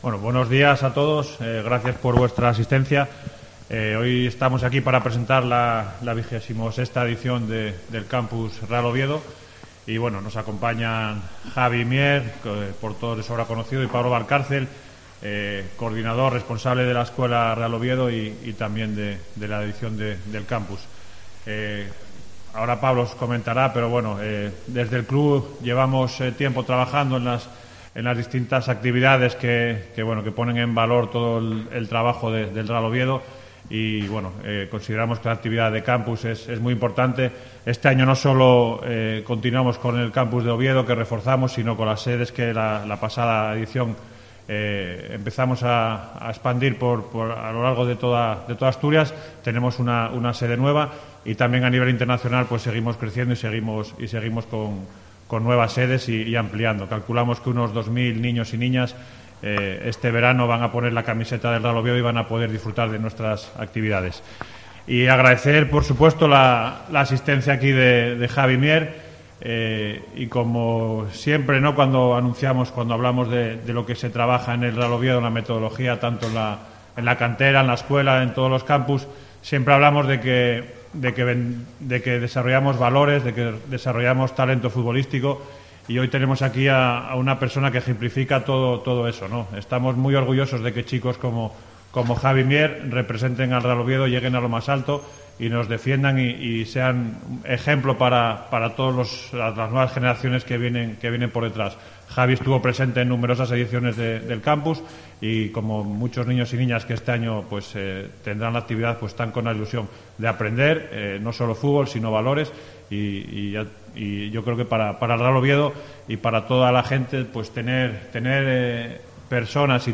Rueda de prensa Campus Real Oviedo